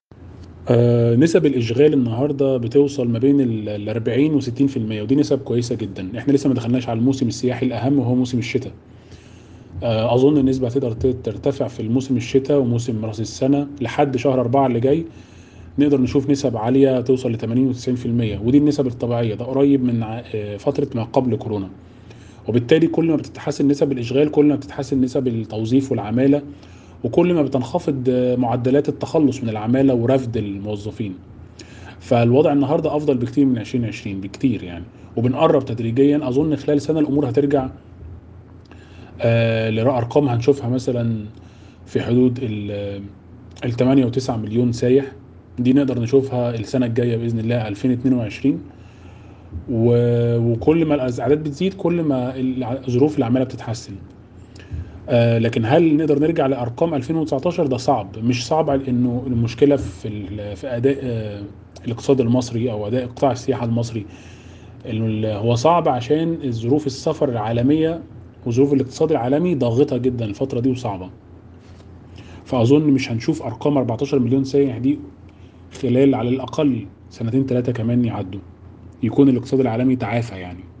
حوار
محلل اقتصادي